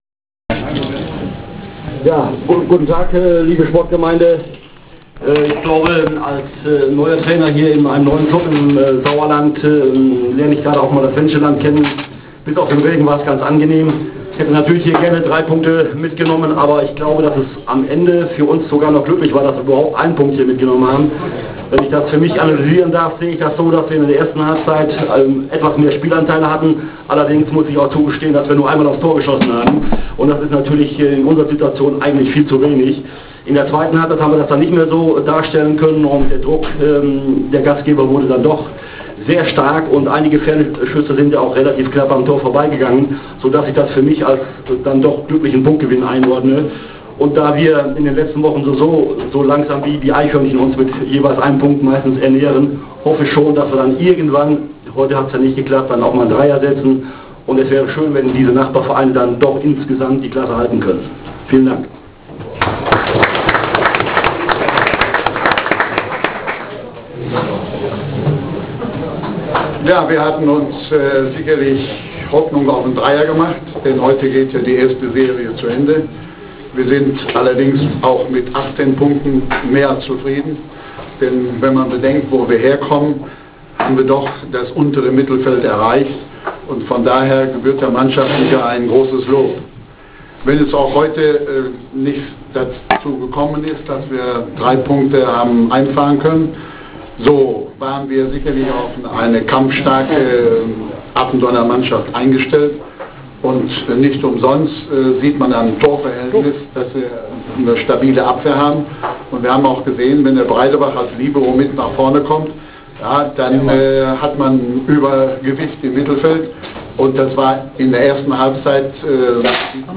Pressekonferenz zum Download
(mit einem seltenen und seit Jahren vergessenen Spezial: Fragen der Presse ;-) )